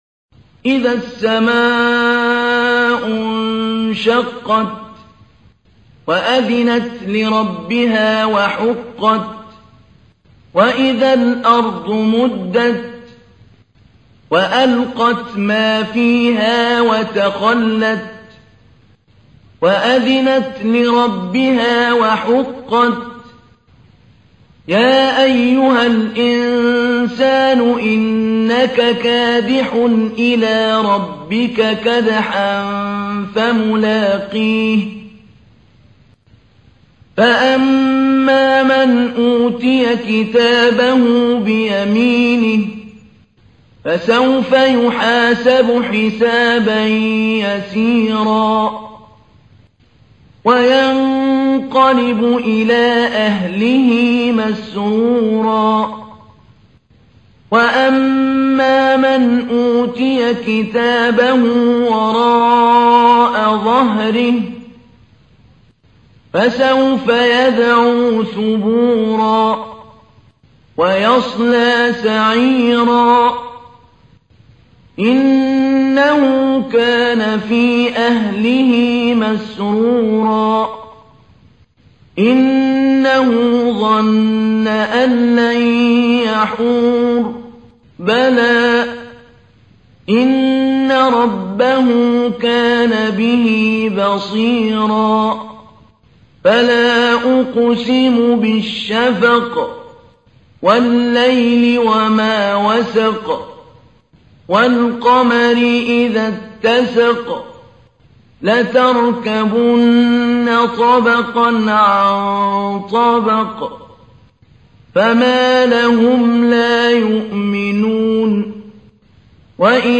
تحميل : 84. سورة الانشقاق / القارئ محمود علي البنا / القرآن الكريم / موقع يا حسين